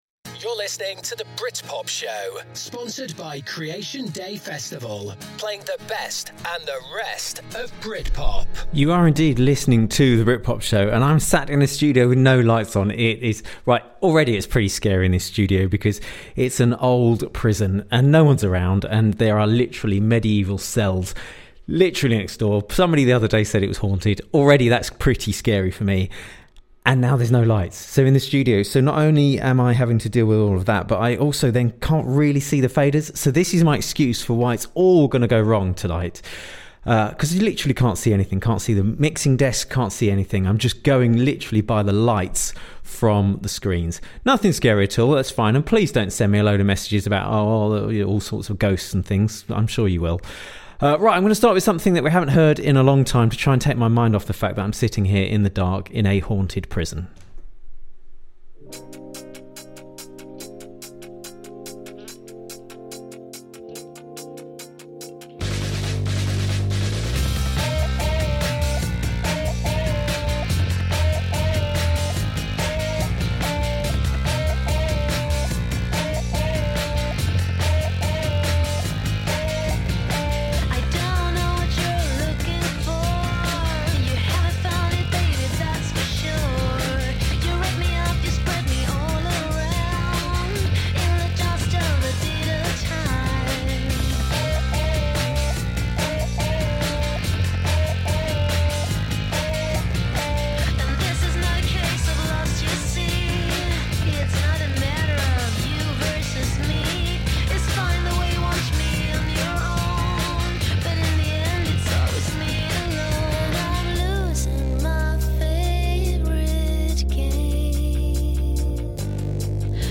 A mix of classic Britpop tracks, Britpop songs you thought you’d forgotten, and some you ought to know but don’t yet - with the odd interview with Britpop royalty thrown in!